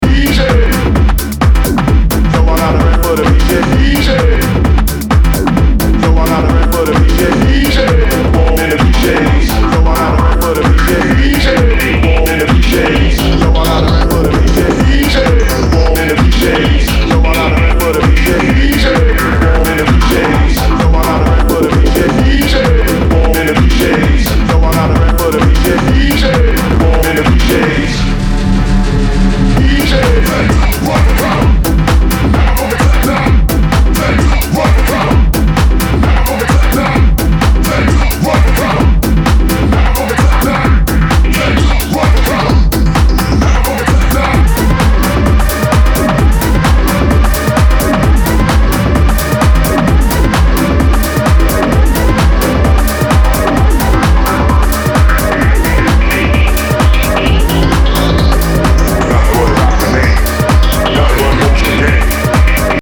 • Deep House, Tech House